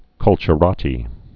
(kŭlchə-rätē)